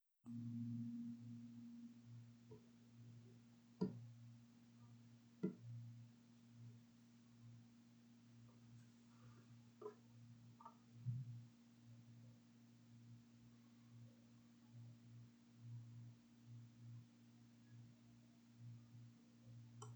backnoise (4).wav